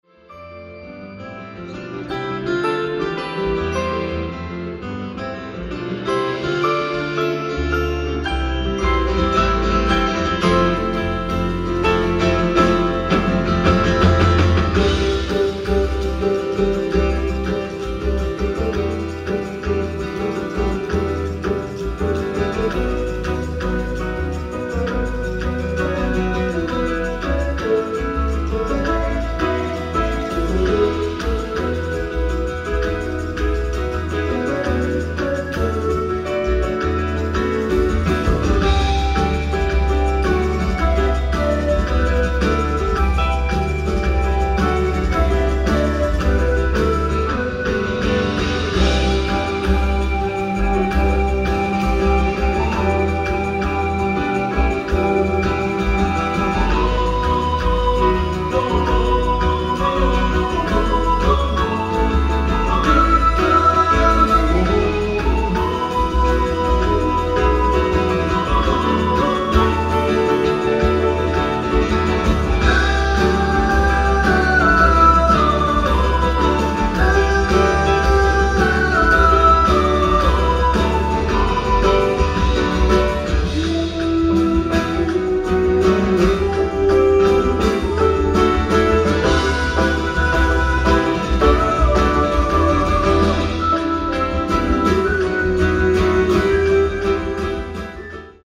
ライブ・アット・ハミングバード・センター、トロント、カナダ 02/18/2005
※試聴用に実際より音質を落としています。